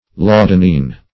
Search Result for " laudanine" : The Collaborative International Dictionary of English v.0.48: Laudanine \Lau"da*nine\, n. [From Laudanum .]